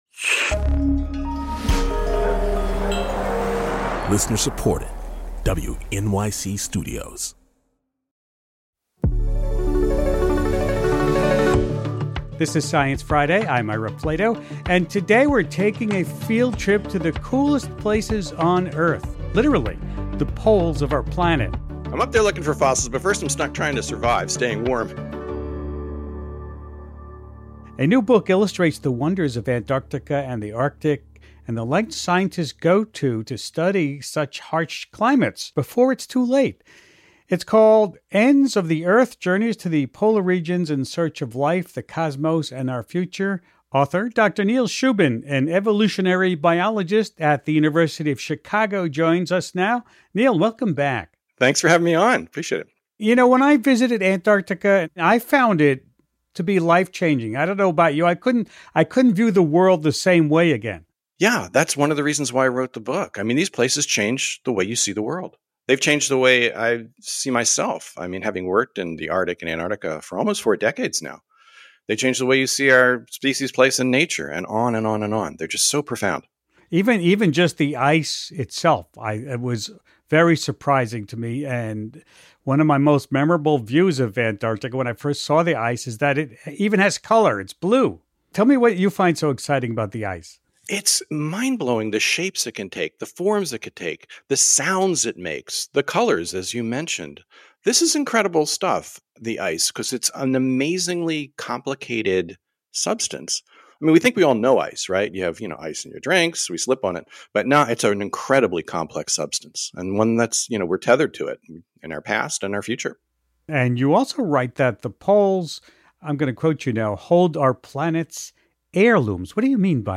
Host Ira Flatow talks with author Dr. Neil Shubin, an evolutionary biologist at the University of Chicago, about some of the wonders found at the ends of the Earth, and the threats they’re facing.